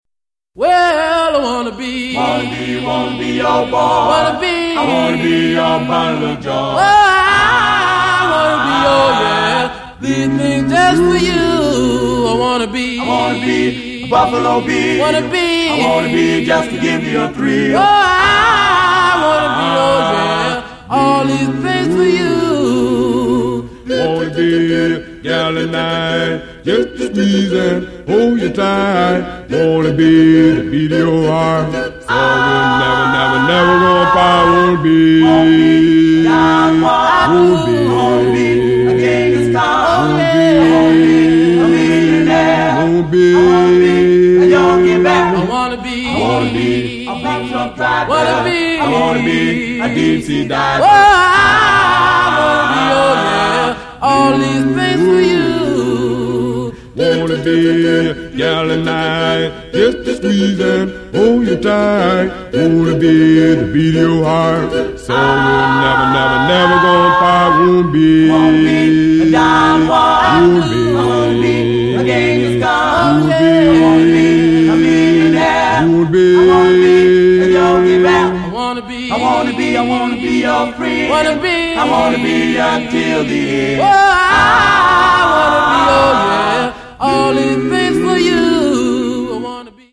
R&B / Doo Wop